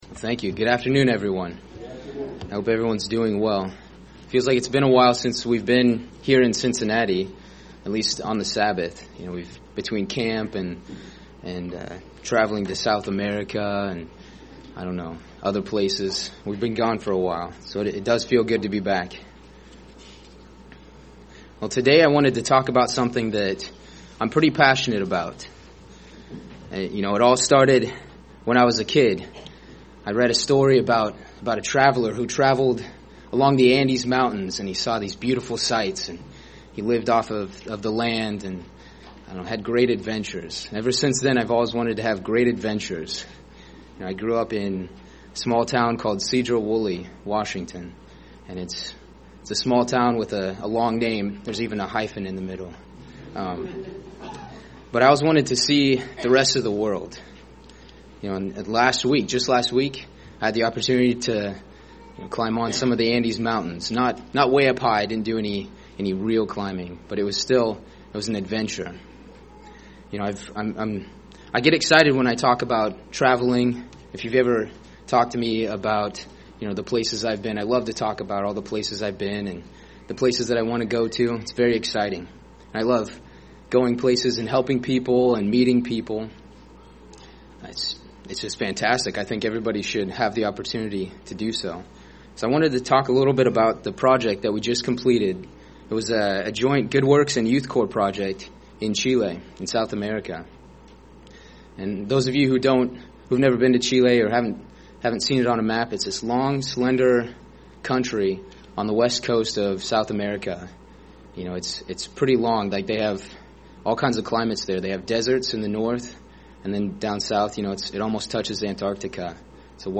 Sermons
Given in Cincinnati East, OH